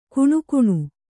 ♪ kuṇukuṇu